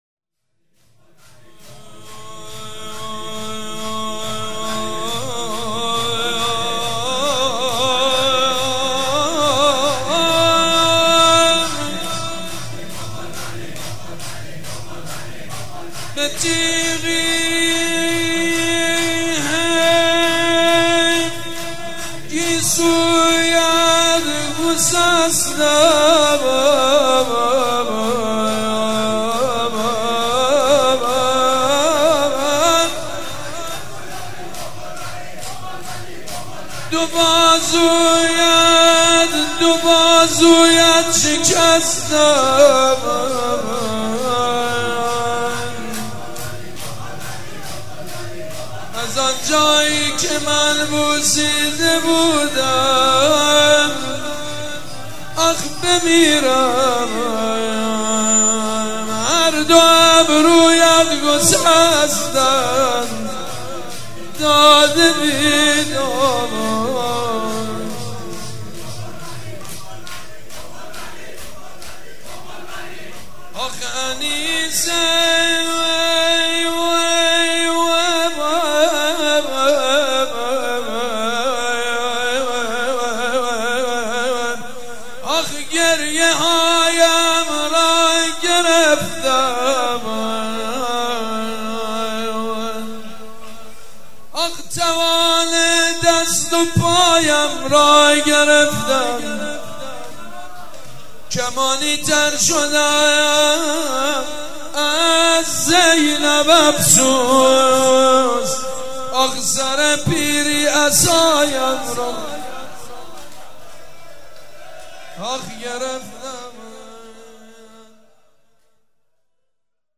مراسم شهادت حضرت ام البنین سلام الله علیها
شور
مداح